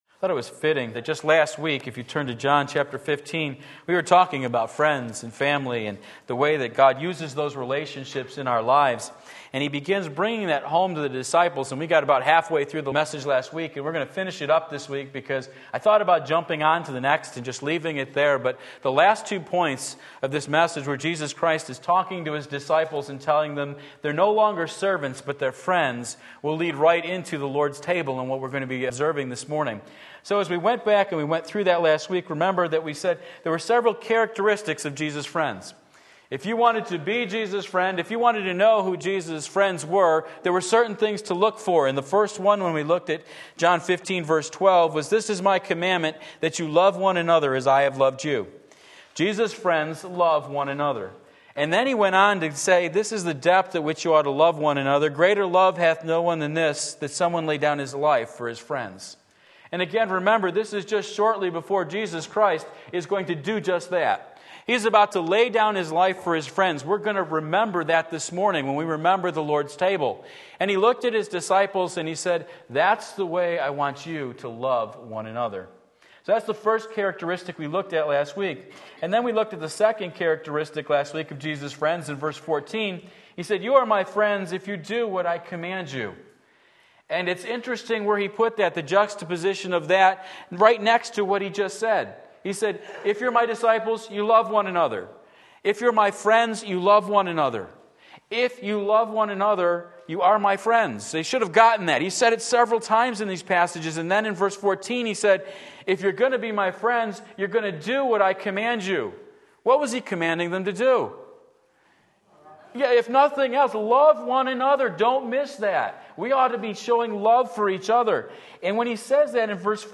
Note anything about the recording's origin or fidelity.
Friends of Jesus – Part 2 John 15:12-17 Sunday Morning Service, July 2, 2017 Believe and Live!